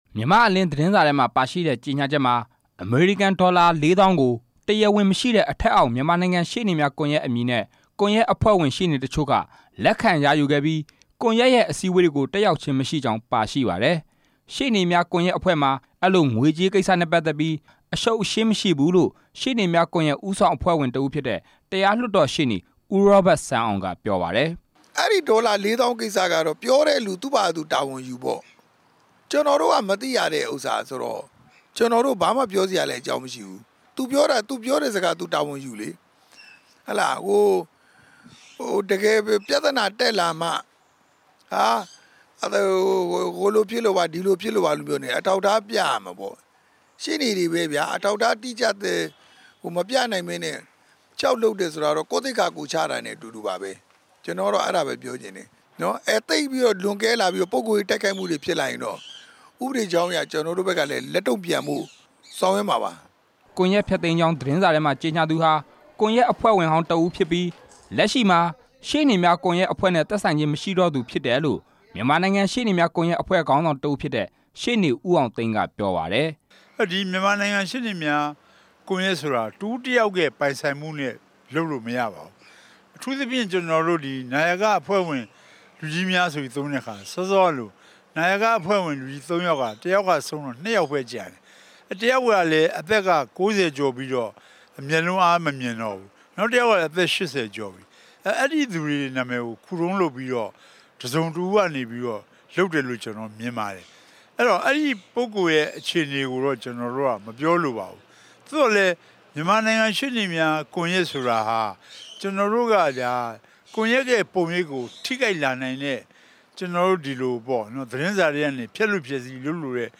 မြန်မာနိုင်ငံရှေ့နေများကွန်ရက် သတင်းစာရှင်းလင်း
ပြီးခဲ့တဲ့ရက်ပိုင်းက မြန်မာနိုင်ငံရှေ့နေများကွန်ရက် ဖျက်သိမ်းကြောင်း အစိုးရထုတ် မြန်မာ့အလင်း သတင်းစာမှာပါတဲ့ ကြေညာချက်ဟာ မမှန်ကန်ကြောင်း ဒီနေ့ ရန်ကုန်မြို့ တော်ဝင်နှင်းဆီခန်းမမှာ ကျင်းပခဲ့တဲ့ မြန်မာနိုင်ငံရှေ့နေများကွန်ရက် ညီလာခံမှာ တာဝန်ရှိသူတွေက ပြောပါတယ်။